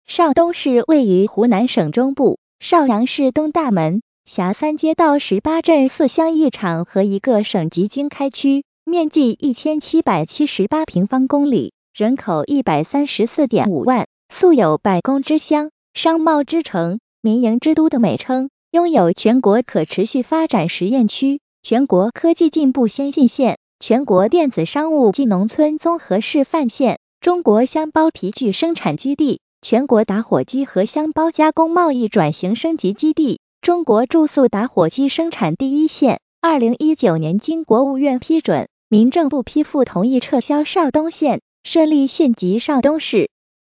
文字转语音
一款全面功能、简单易用、准确率高的文字语音转换工具,借助AI人工智能技术,提供专业水准的文字转语音功能,拥有多种不同发音人、配音人以及主播音色类型,以提供流畅自然的语音合成服务